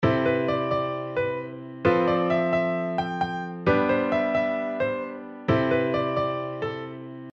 今度は、ノンコードトーンだけにしてみます。
ノンコードトーンだけの場合
なんとなく落ち着かず、しっくり来ない感じがずっと続きますね。